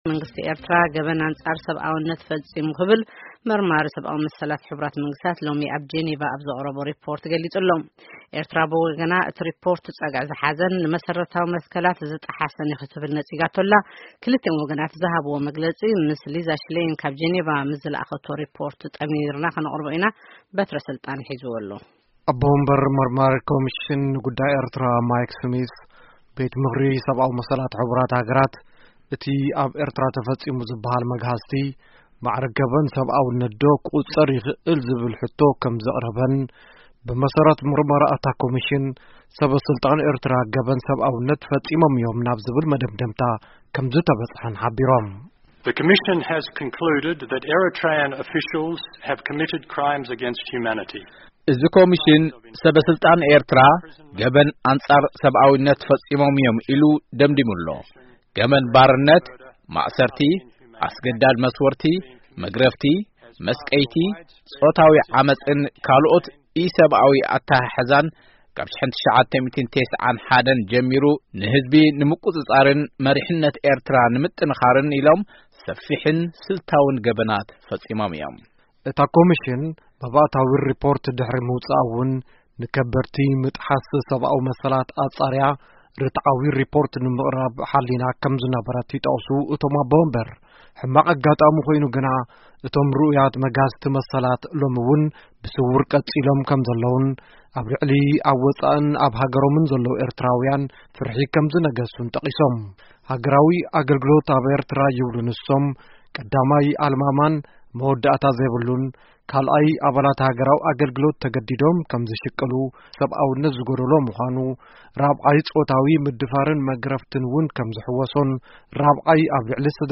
ዝተዋደደ ሪፖርት መድረኽ ሰብኣዊ መሰላት ሕ/ሃ ኣብ ጄኔቫ